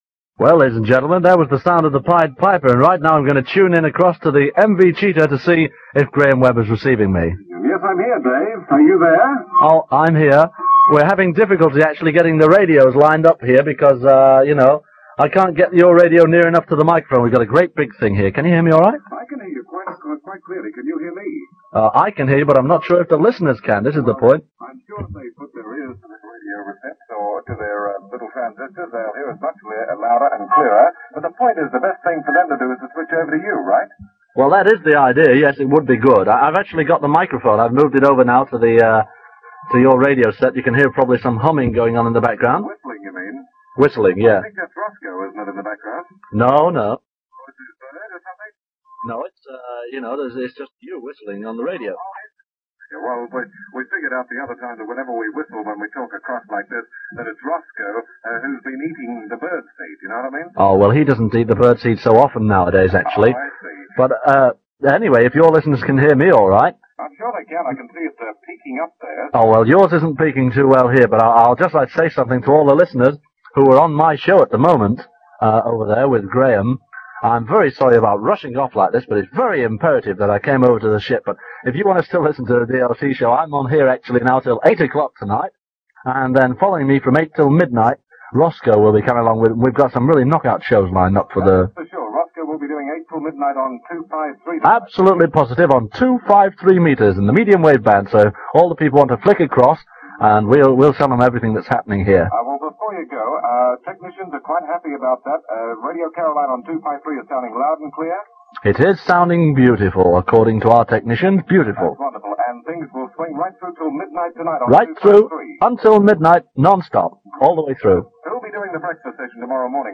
Dave Lee Travis testing 253 from the Mi Amigo
still broadcasting on 199 from the Cheeta II.